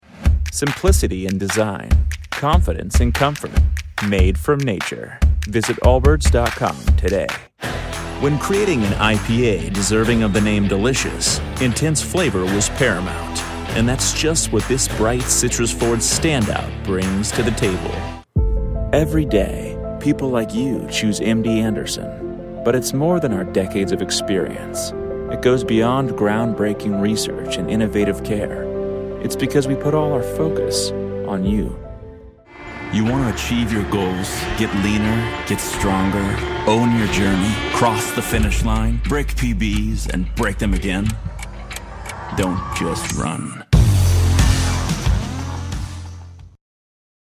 Englisch (Amerikanisch)
Kommerziell, Natürlich, Zuverlässig, Warm
Kommerziell